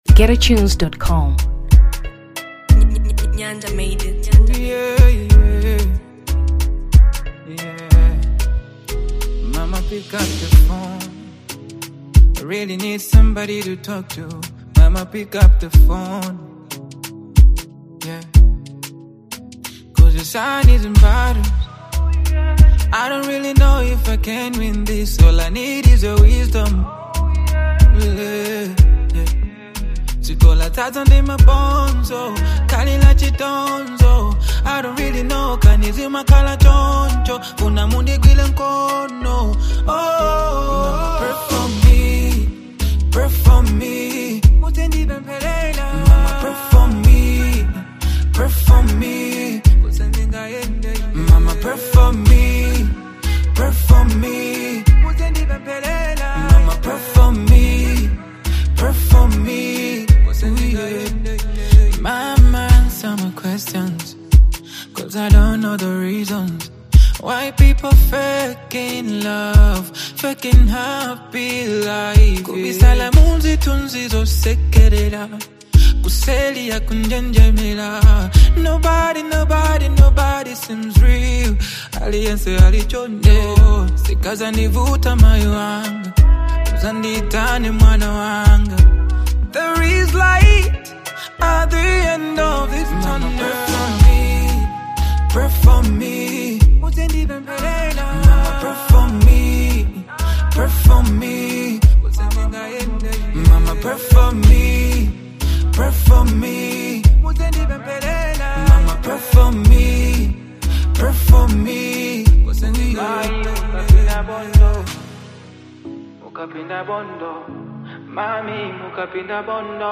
Afro 2023 Malawi